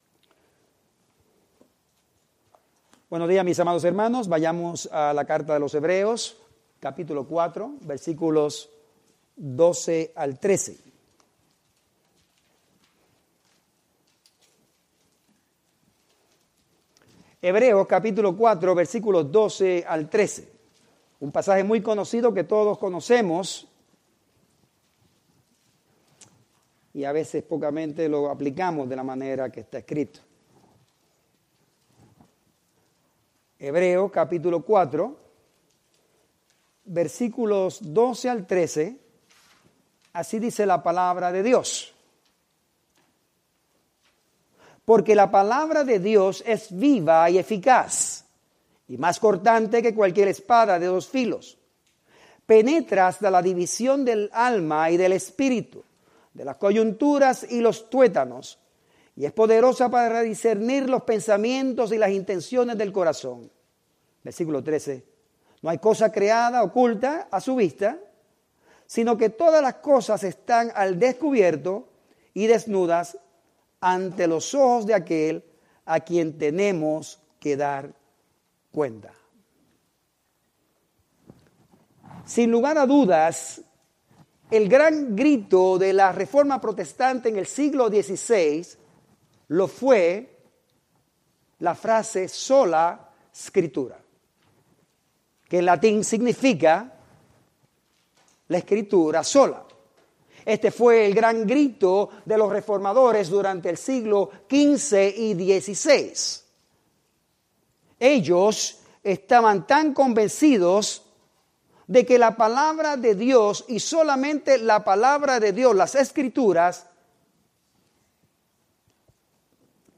Spanish Bible Study